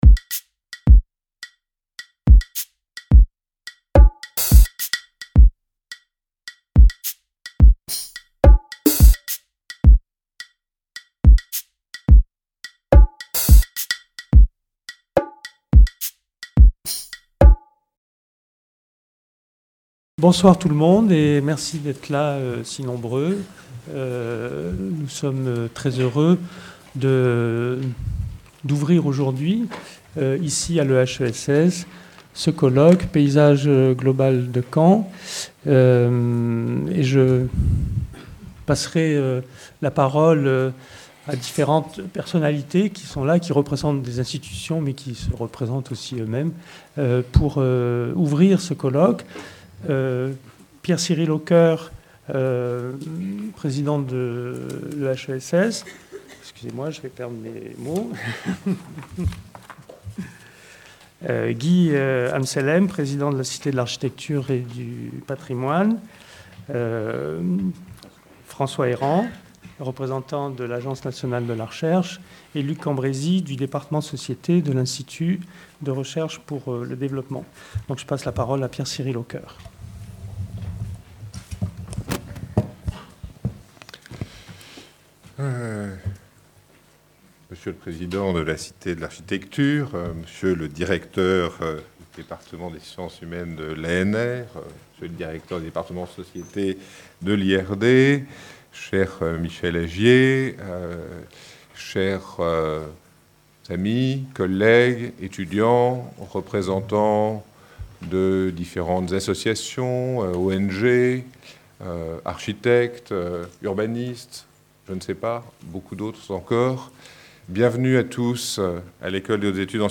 1- Ouverture et conférence inaugurale | Canal U